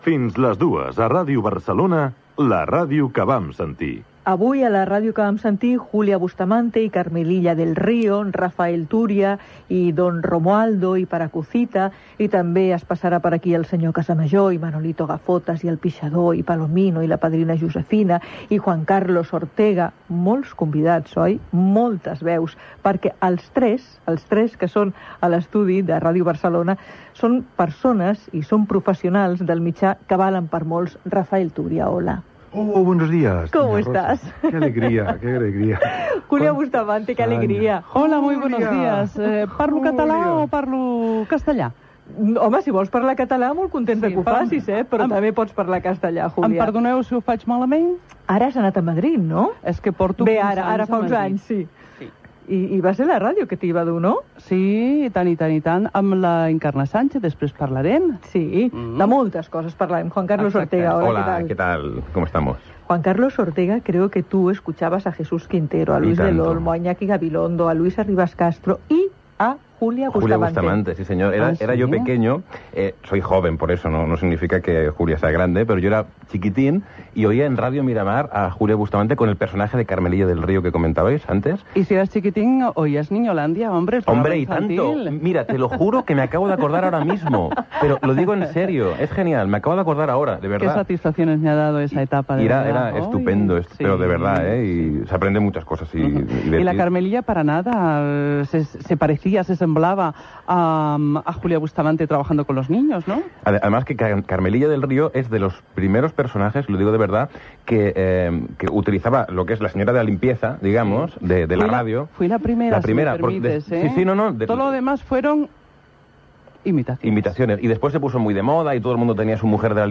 Indicatiu del programa.
Divulgació